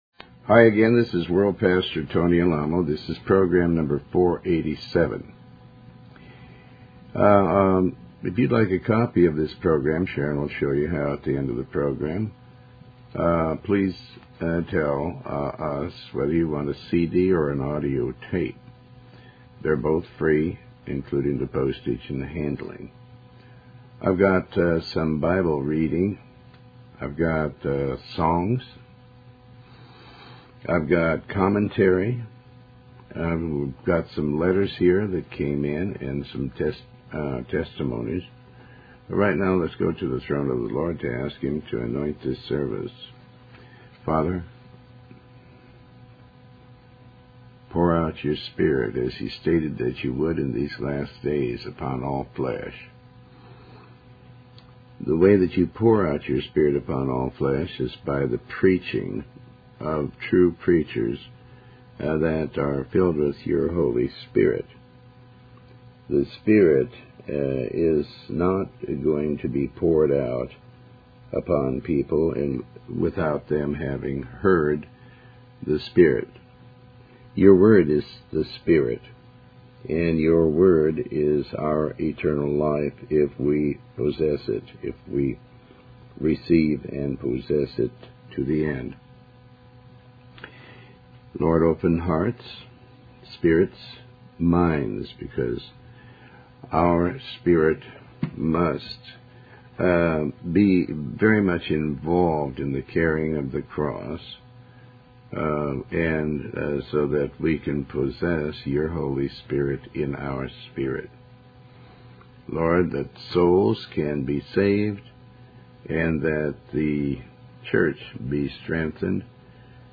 Tony Alamo Talk Show